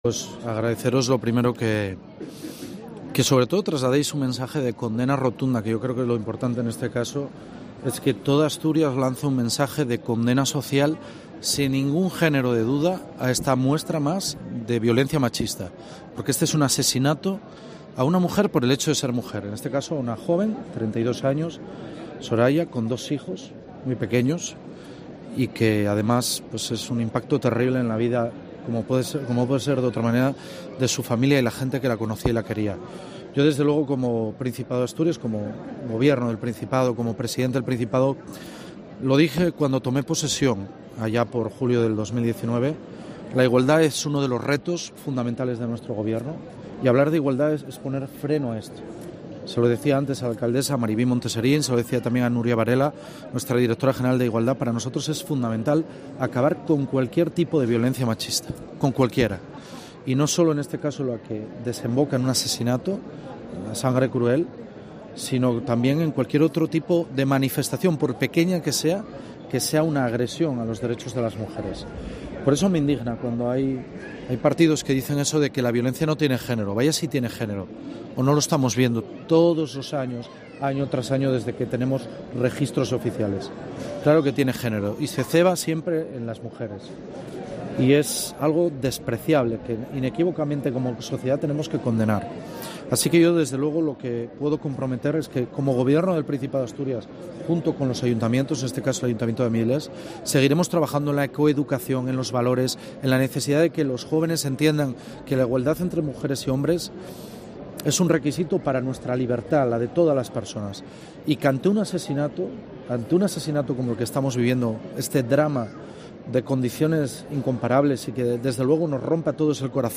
El presidente de Asturias, Adrián Barbón, en la concentración de repulsa por el asesinato machista de Avilés